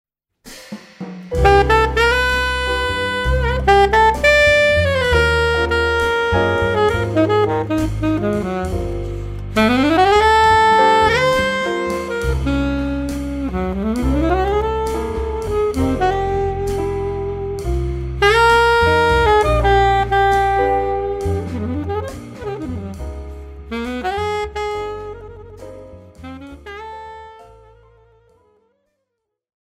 alto and sopranino saxophones
piano
bass
drums